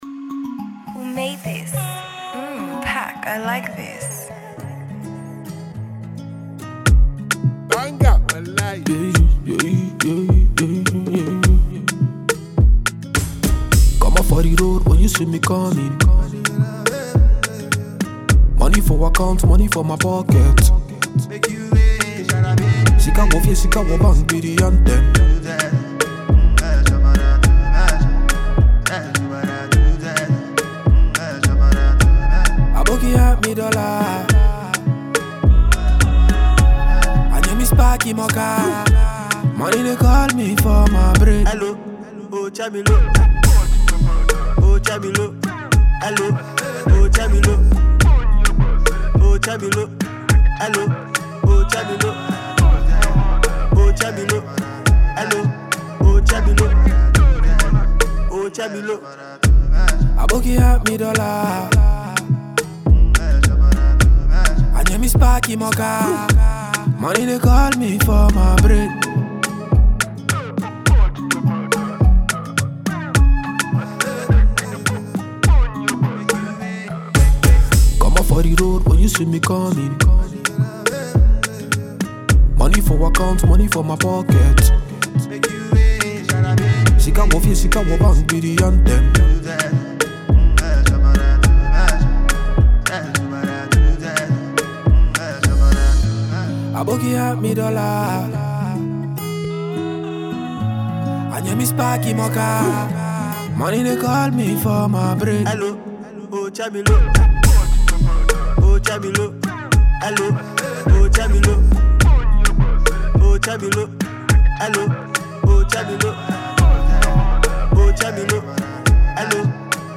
This dope tune is for all to enjoy.